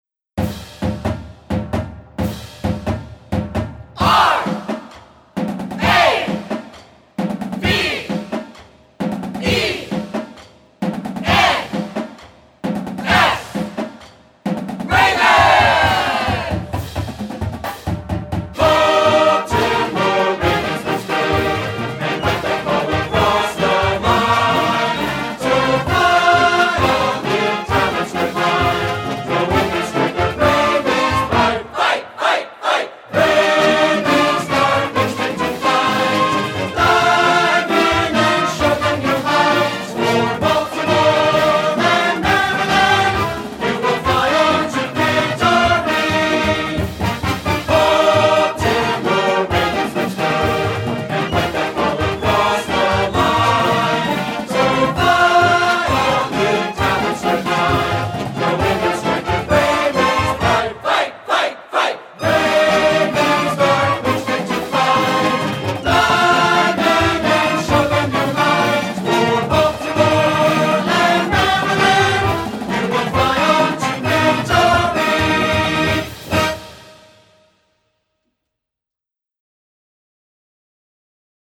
Foa a mp3 with vocal